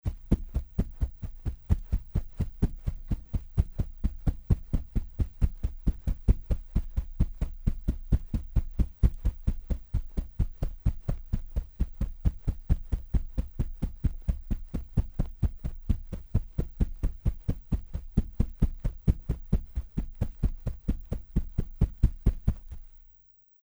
奔跑偏低频2－YS070525.mp3
通用动作/01人物/01移动状态/土路/奔跑偏低频2－YS070525.mp3
• 声道 立體聲 (2ch)